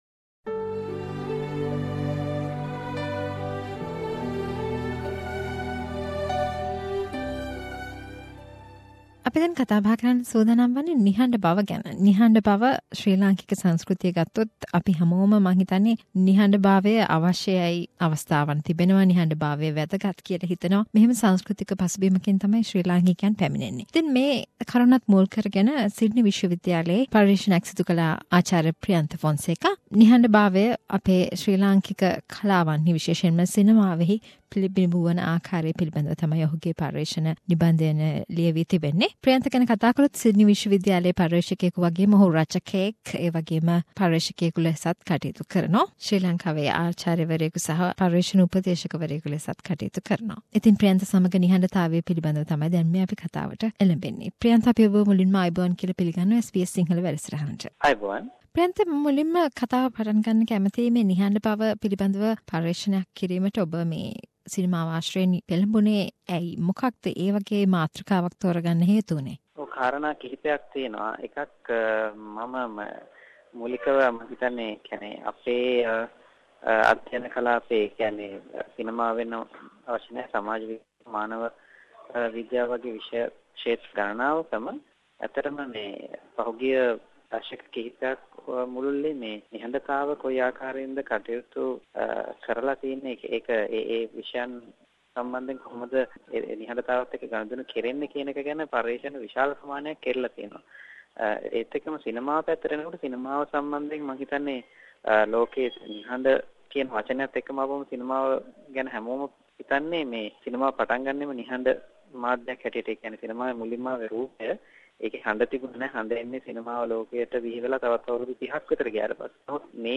A discussion about silence in Sri Lankan cinema and art